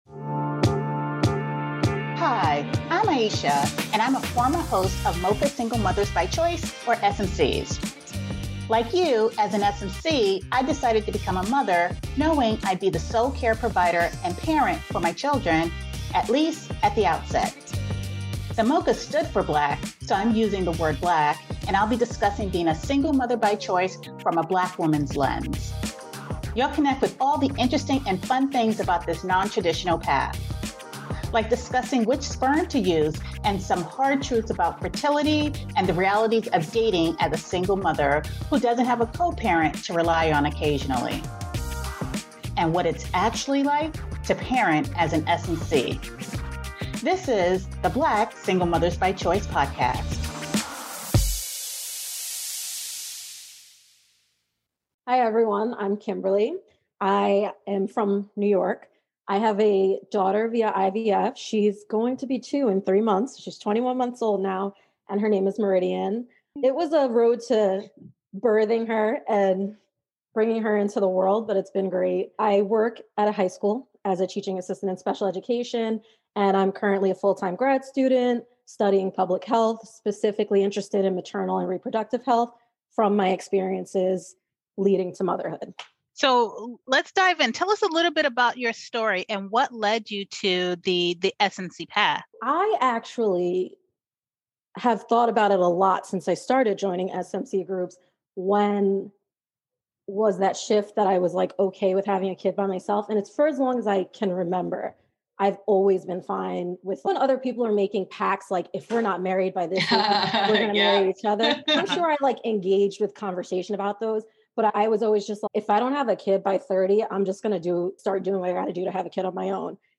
The episode highlights the importance of support systems, the realities of fertility struggles, and the resilience required to navigate this unique path. Tune in for an inspiring conversation filled with practical advice, emotional insights, and a celebration of non-traditional family structures.